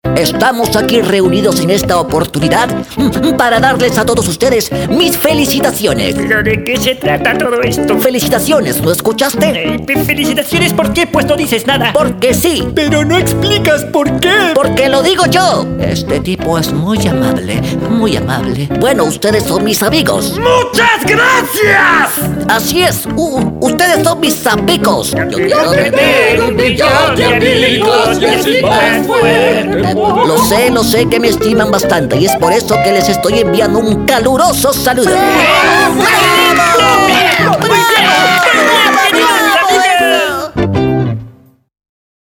Animation
Ich habe mein eigenes Studio.